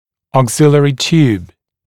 [ɔːg’zɪlɪərɪ t(j)uːb] [ʧuːb][о:г’зилиэри т(й)у:б] [чу:б]вспомогательная трубка-замок